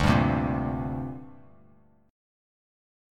D7sus4#5 chord